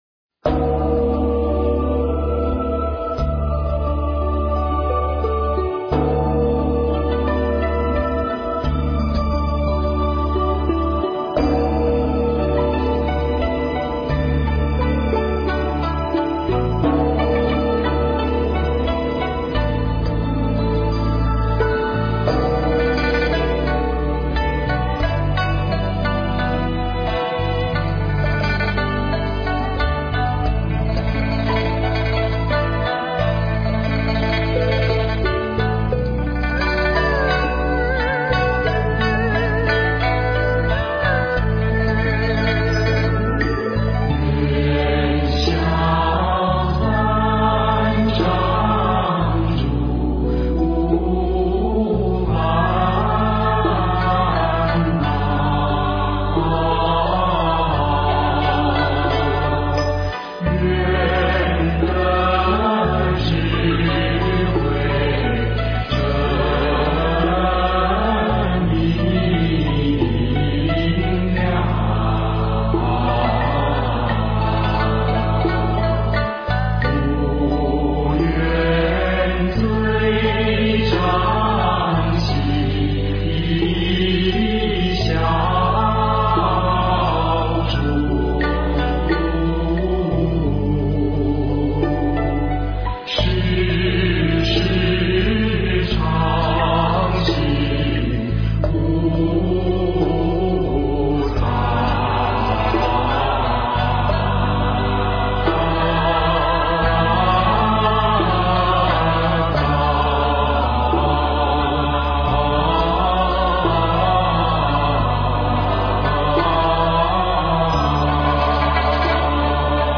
回向偈--寺院 经忏 回向偈--寺院 点我： 标签: 佛音 经忏 佛教音乐 返回列表 上一篇： 赞佛偈--寺院 下一篇： 晚课--深圳弘法寺 相关文章 早课--龙泉寺 早课--龙泉寺...